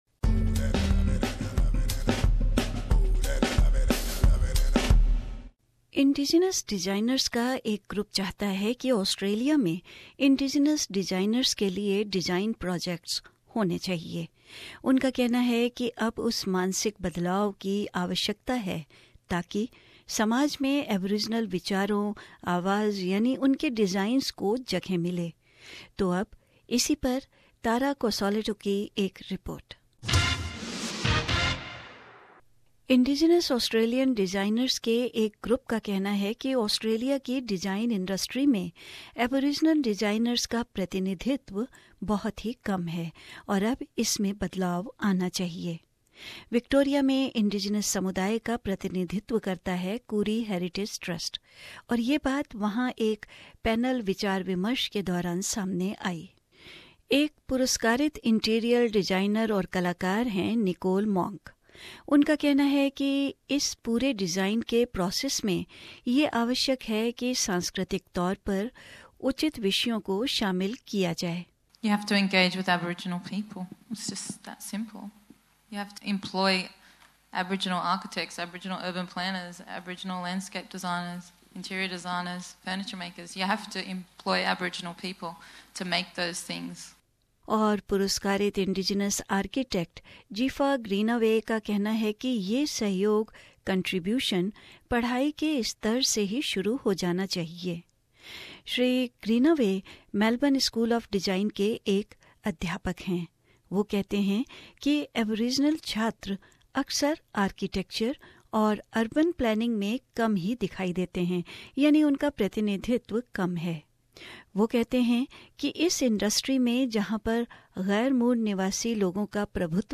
SBS Hindi